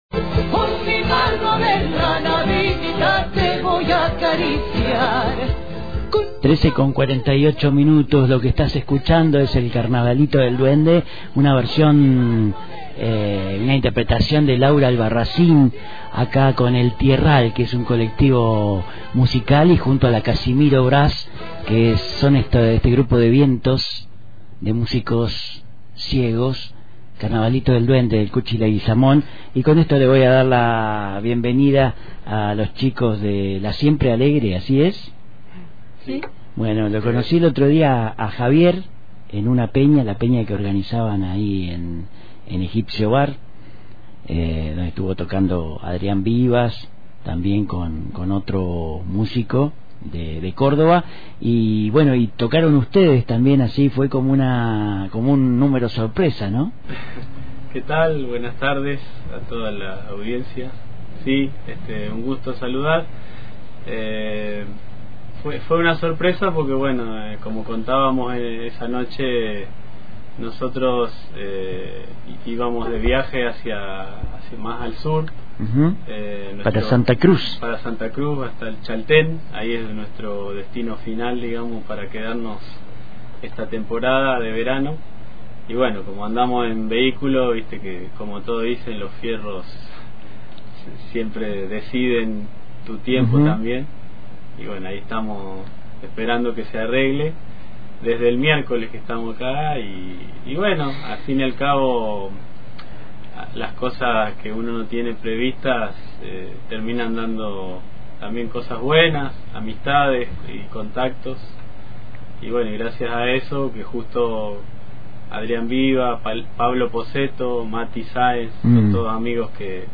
un trío viajero
una banda de folclore
violín